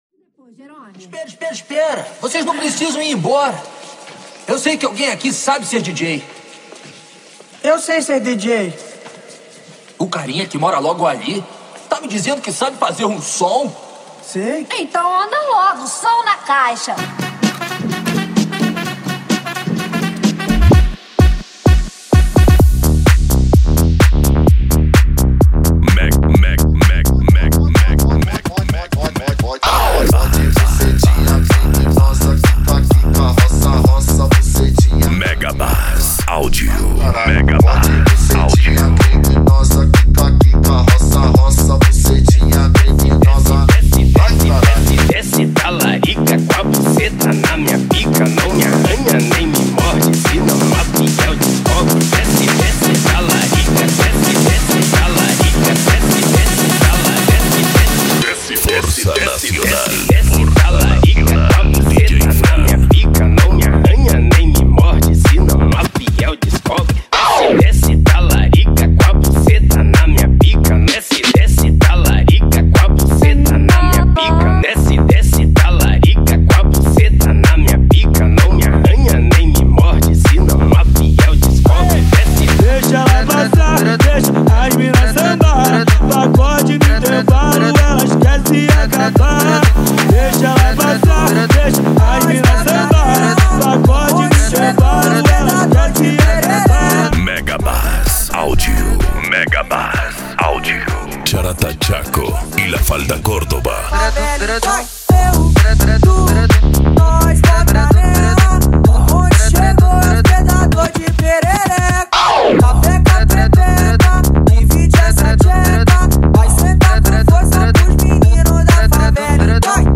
Bass
Funk
Mega Funk
Minimal